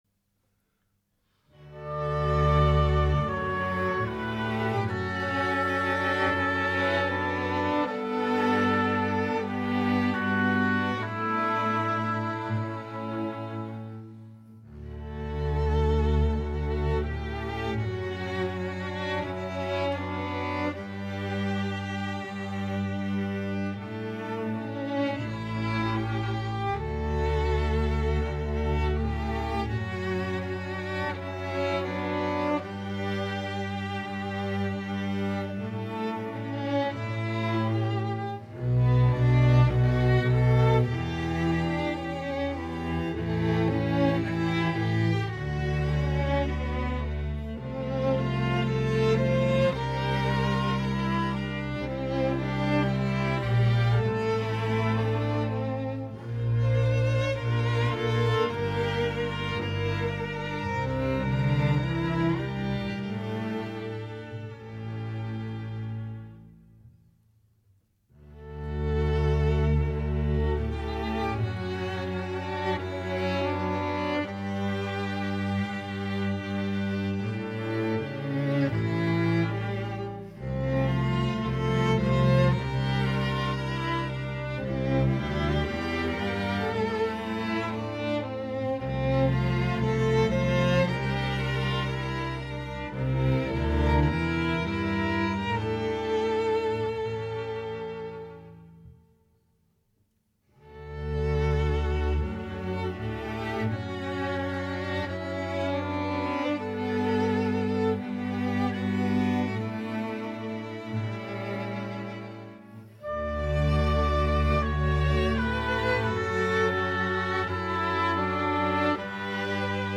Disponibili: Audio/demo, partitura e parti staccate.